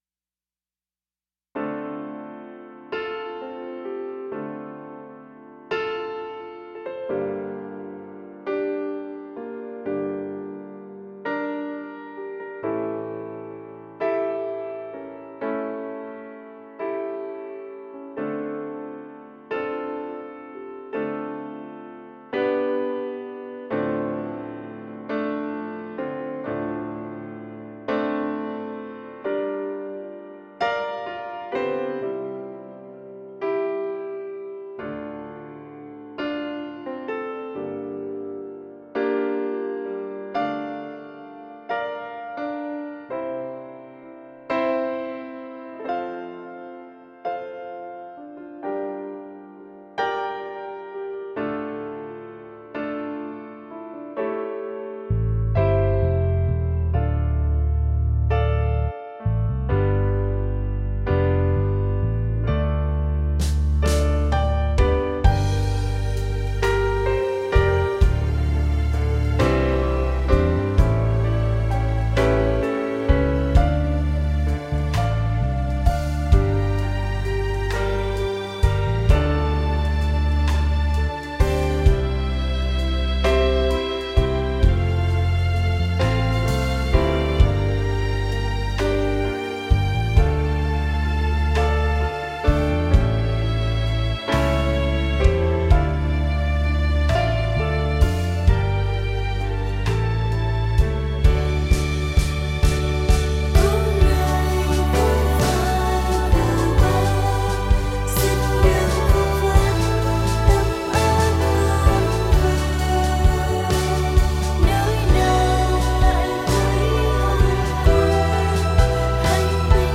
Nghe nhạc hòa tấu karaoke: